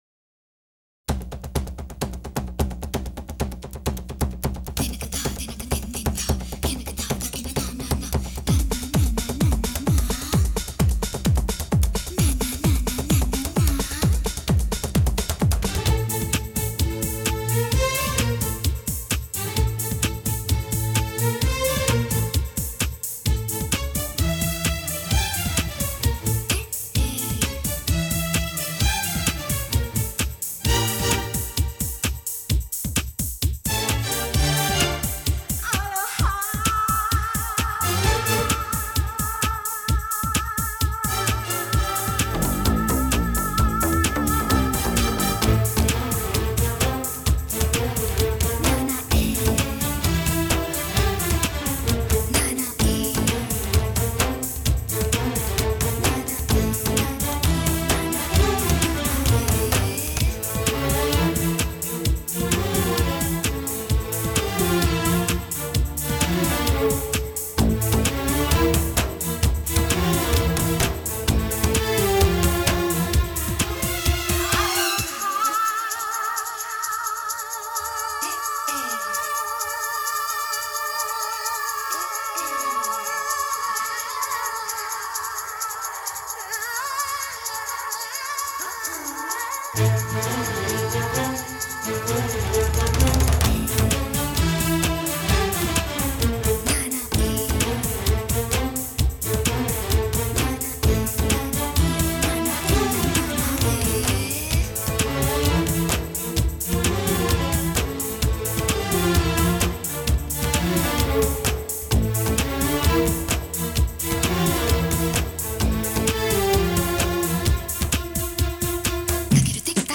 (Instrumental)